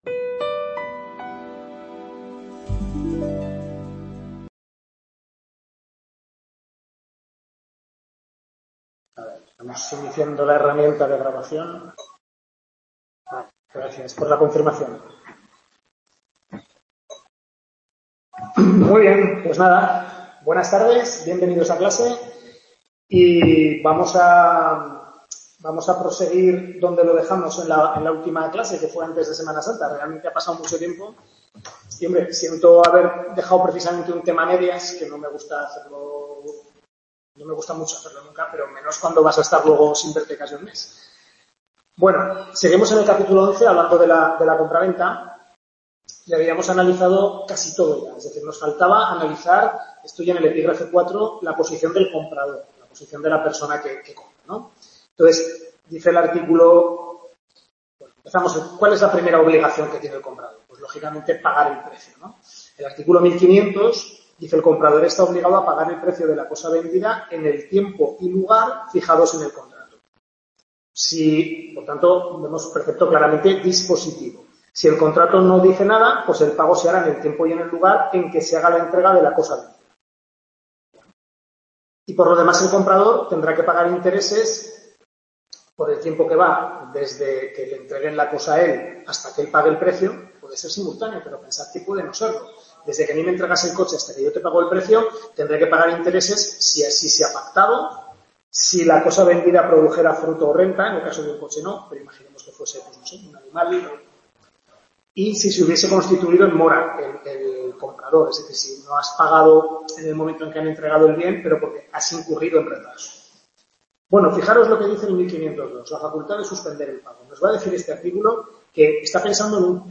Tutoría de Civil II (Contratos), centro de Calatayud, correspondiente a los capítulos 11 (final) a 14 del Manual de la asignatura